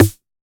RDM_TapeA_MT40-Snr03.wav